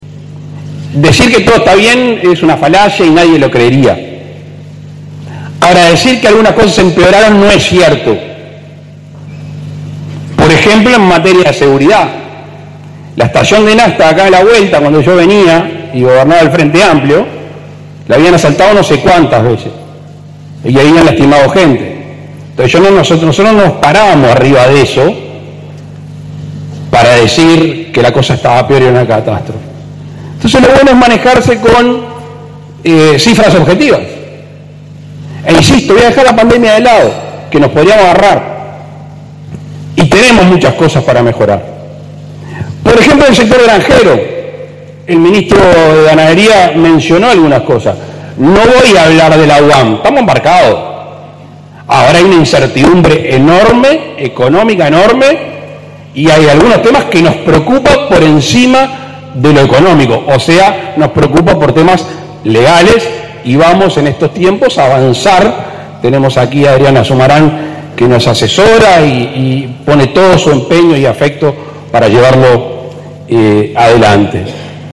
El presidente Luis Lacalle Pou participó este domingo de la asamblea de la Sociedad de Fomento y Defensa Agraria en el Cerro de Montevideo, en la que realizó un discurso donde respondió a la críticas del Frente Amplio durante el Plenario Nacional de este sábado.